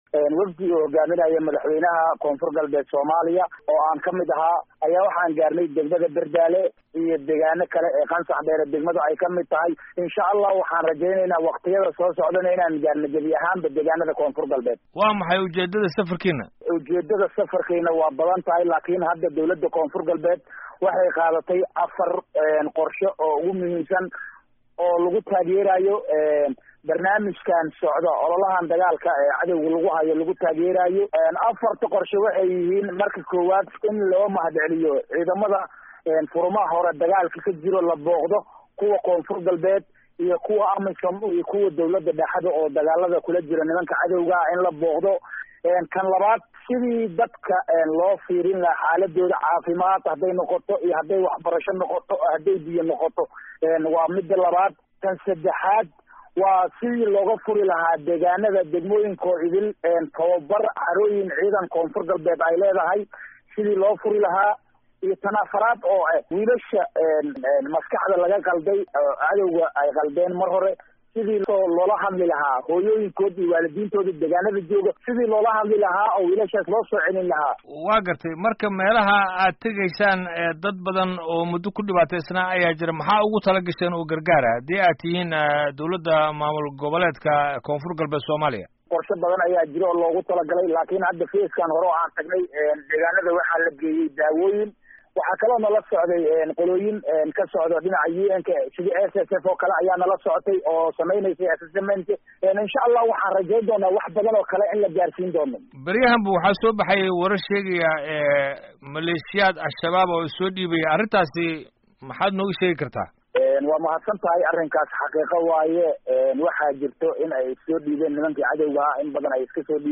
Wareysiga Wasiirka Koonfur Galbeed